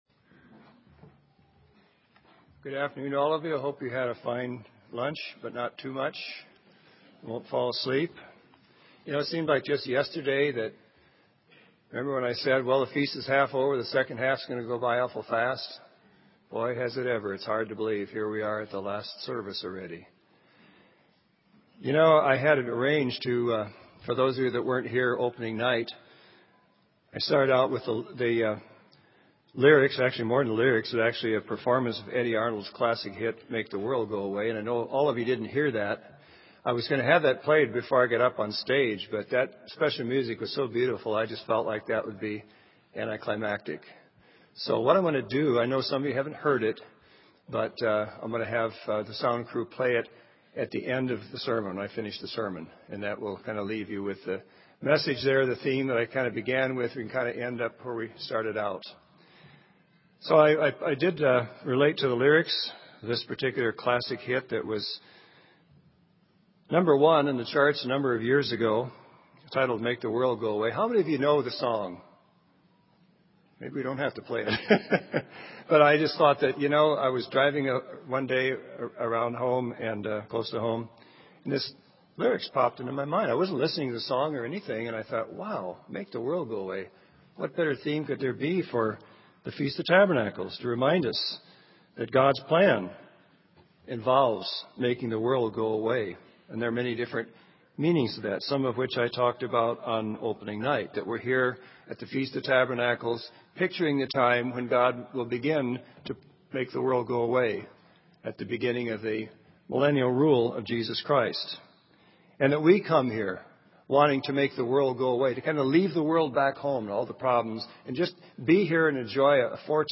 This sermon was given at the Bend, Oregon 2009 Feast site.